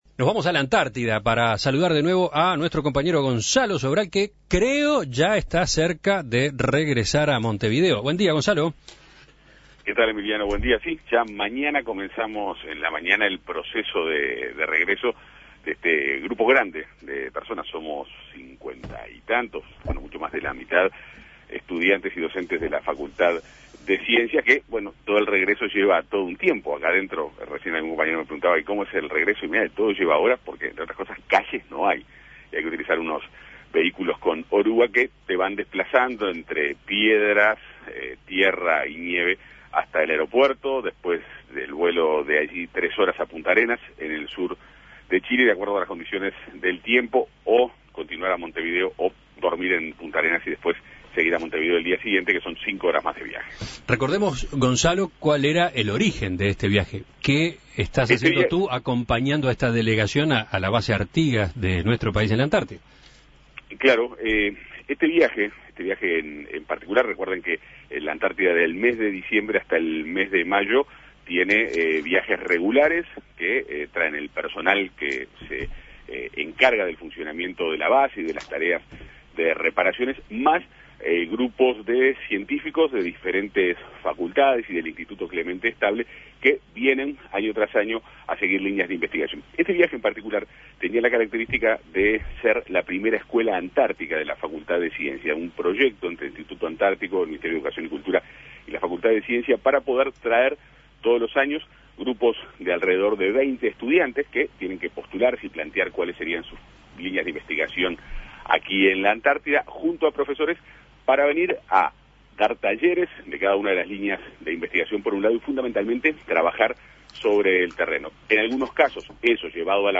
(emitido a las 8.36 hs.)